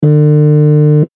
携帯電話バイブレーション2.mp3